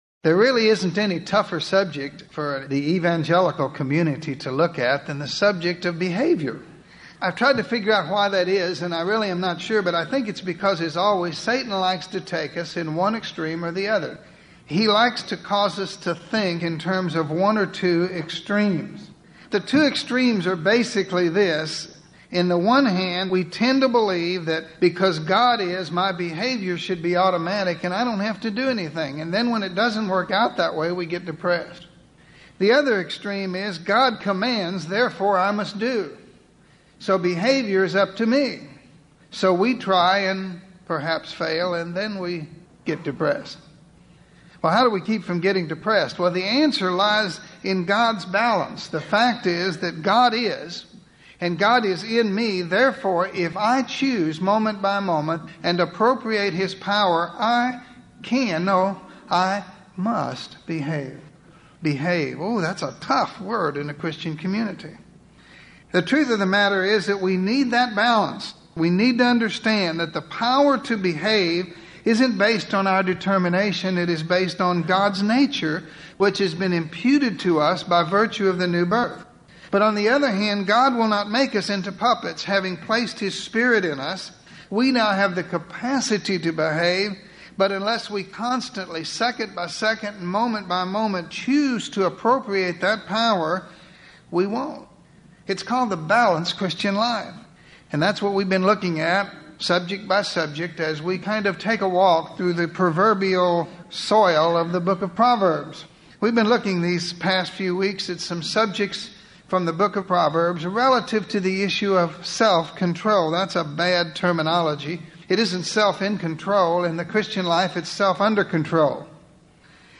In this lesson, we will take a look at both the character of God, which makes morality possible, and the portrait of His holiness, which makes […]